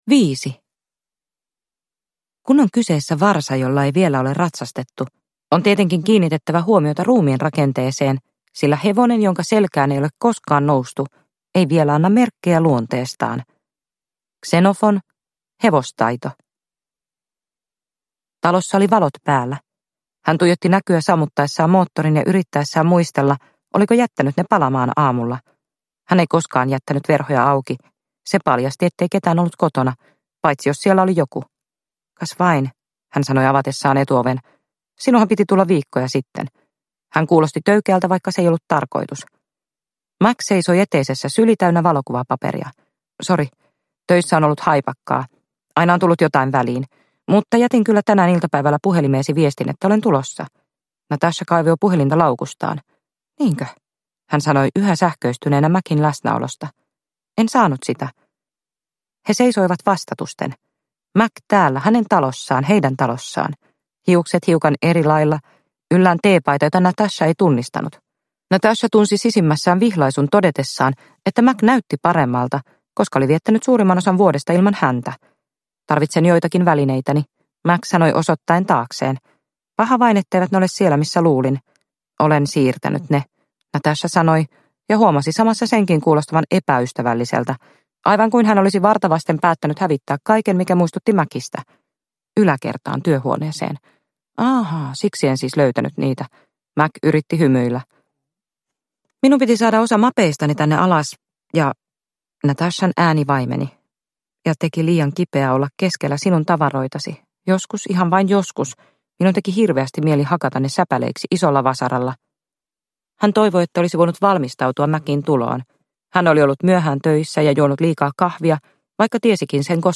Kuinka painovoimaa uhmataan – Ljudbok – Laddas ner